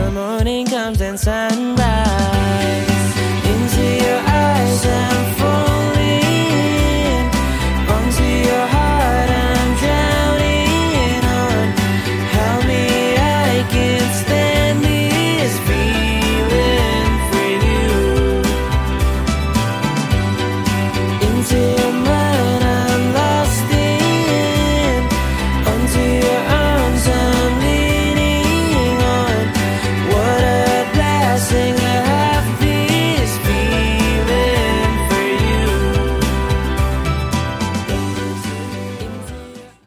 menghadirkan nuansa emosional yang memikat
hangat dan menggoda untuk terus didengarkan.